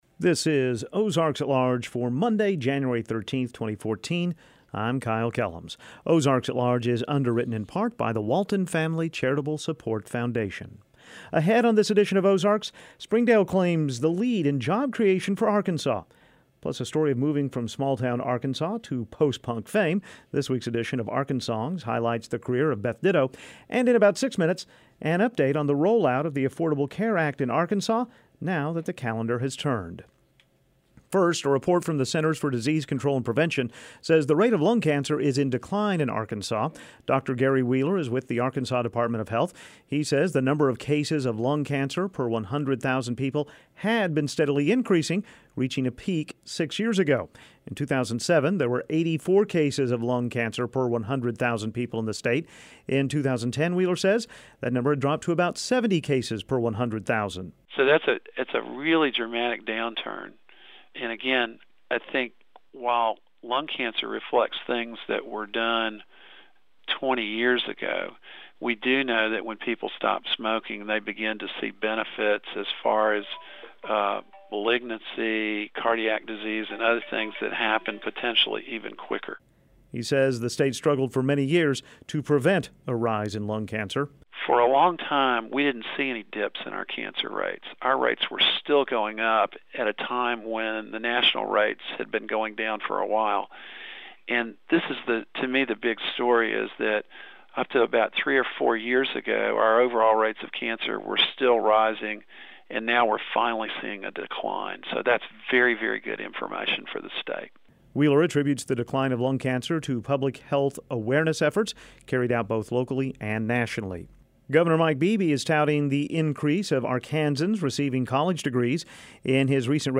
Transition Music: